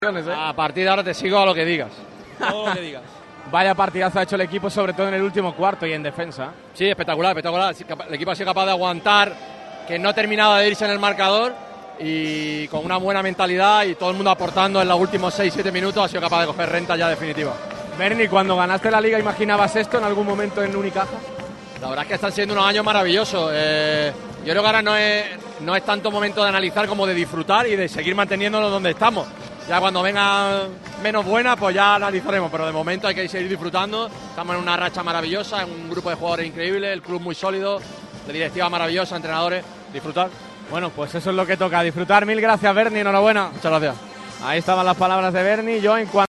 Las reacciones de los campeones de la BCL sobre el parqué del Sunel Arena.